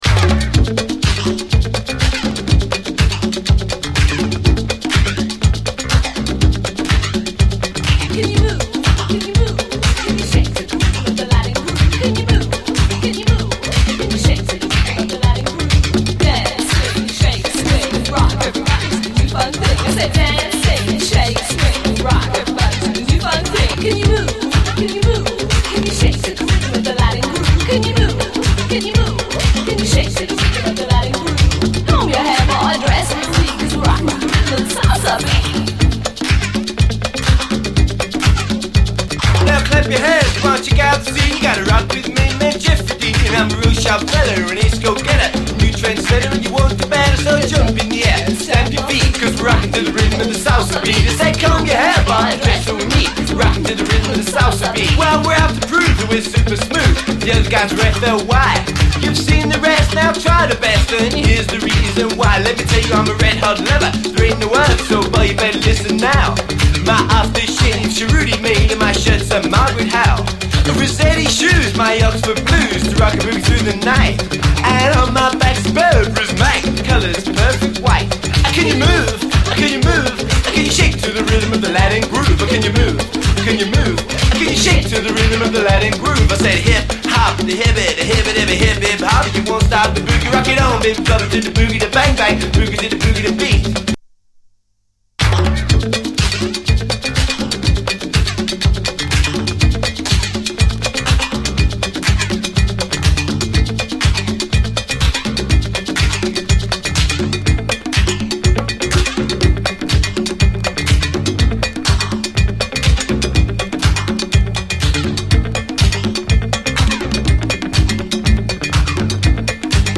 DISCO, 80's～ ROCK, ROCK, FUNK-A-LATINA, HIPHOP
オールドスクール・ディスコ・ラップの裏クラシック
特徴あるラップのフロウやフレーズは頻繁に引用されてます。